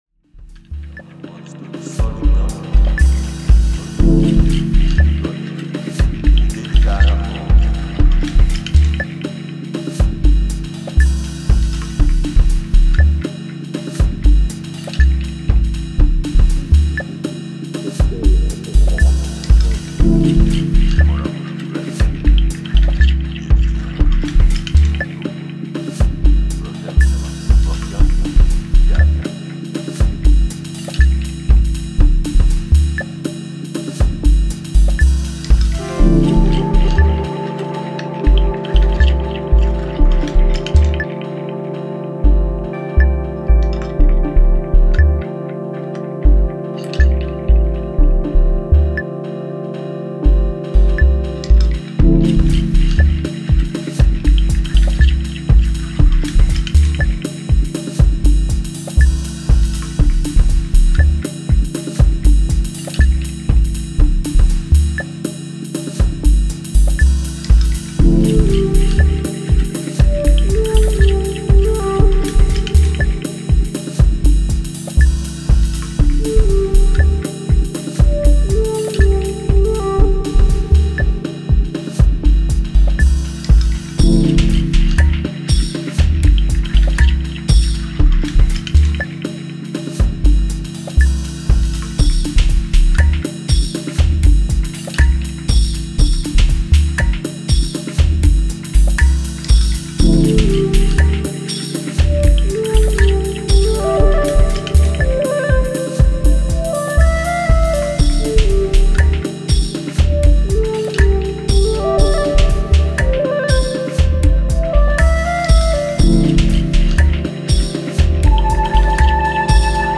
Ambient , Electro , Experimental , House